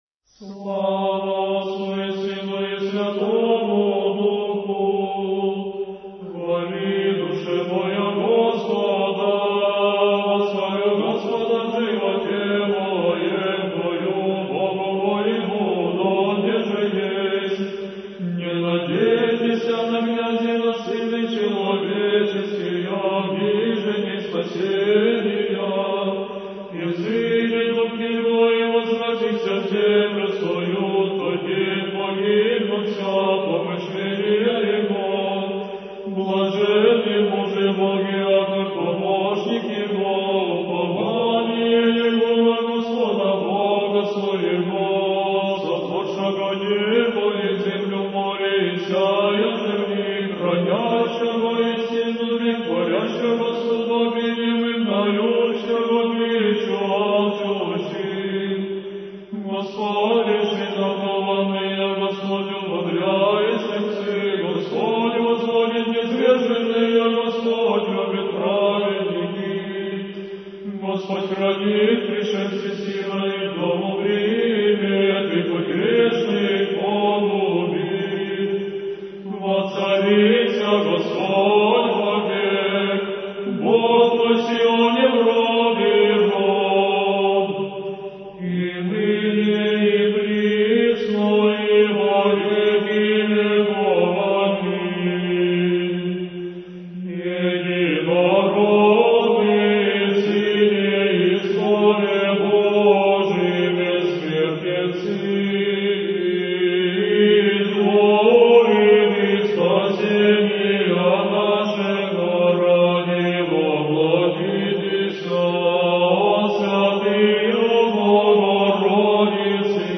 Архив mp3 / Духовная музыка / Русская / Ансамбль "Сретение" /